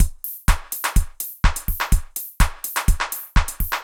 IBI Beat - Mix 7.wav